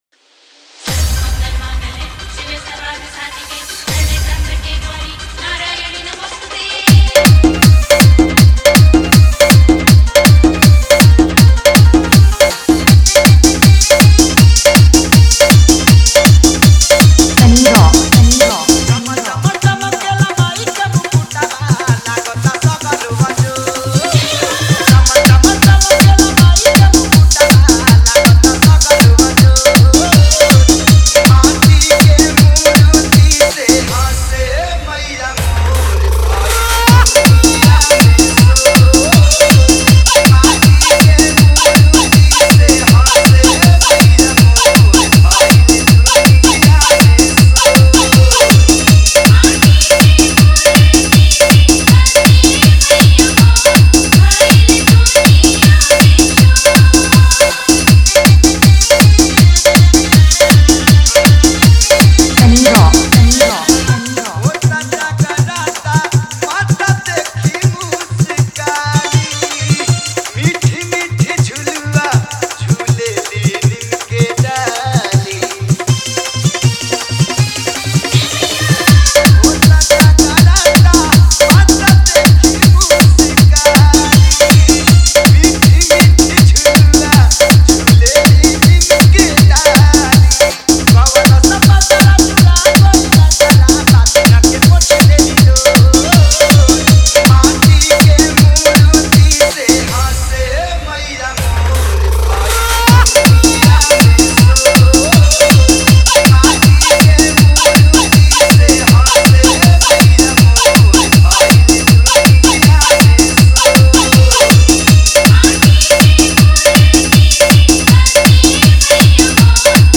All Bhakti Dj Remix Songs